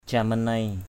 /ca-ma-neɪ/ (d.) ông Từ (trông nom các tháp) = gardien des temples. ong Camanei bimong Po Romé o/ cmn] b|_mU _F@ _r_m^ ông giữ tháp Po Romé.